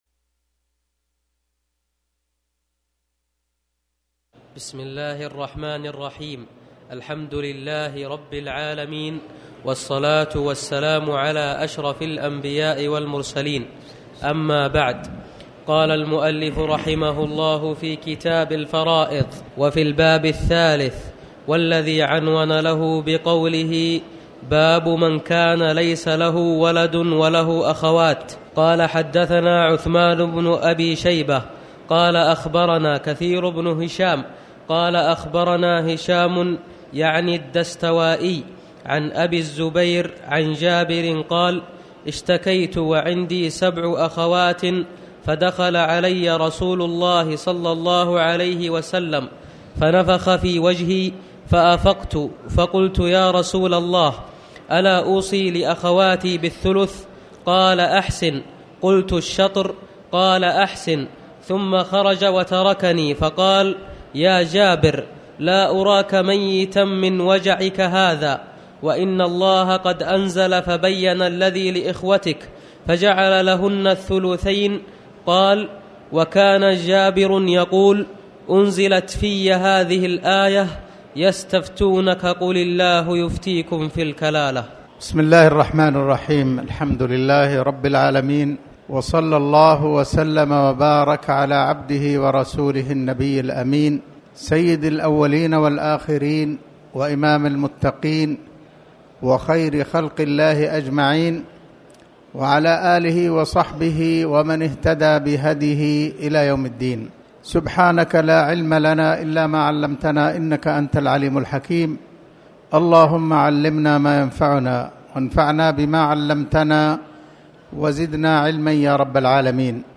تاريخ النشر ٢١ رجب ١٤٣٩ هـ المكان: المسجد الحرام الشيخ